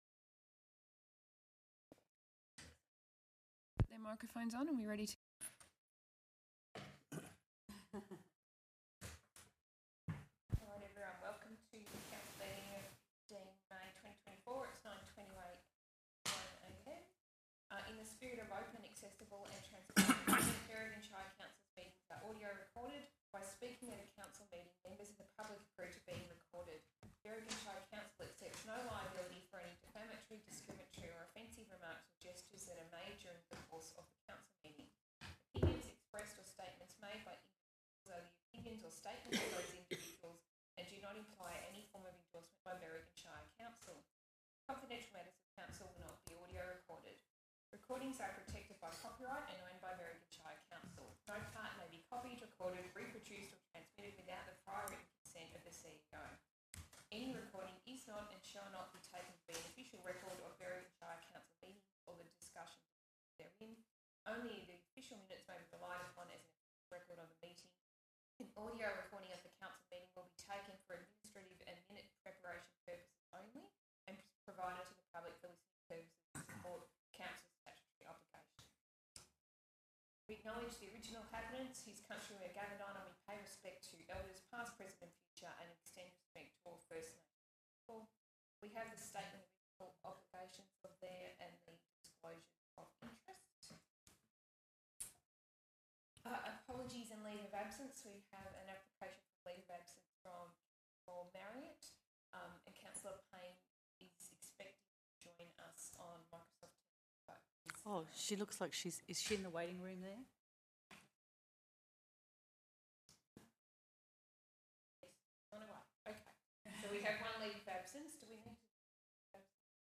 15 May 2024 Ordinary Council Meeting
Meetings are in the Council Chambers, 56 Chanter Street Berrigan